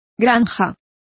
Complete with pronunciation of the translation of homestead.